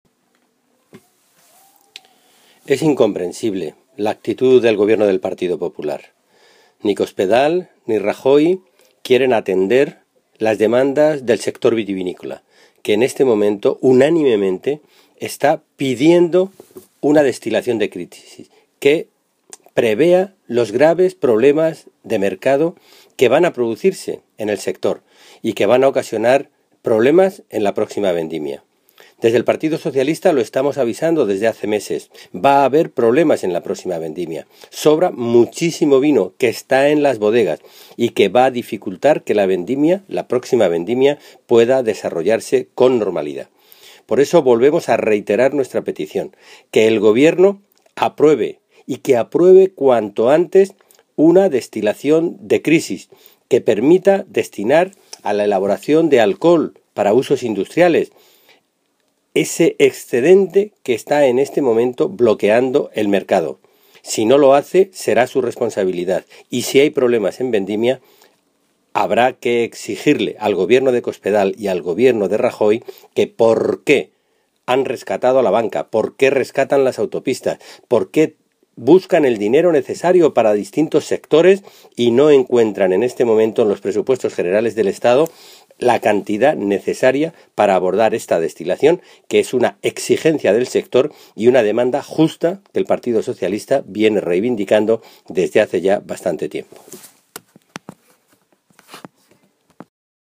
Alejandro Alonso, coordinador del grupo de parlamentarios nacionales del PSOE de Castilla-La Mancha
Cortes de audio de la rueda de prensa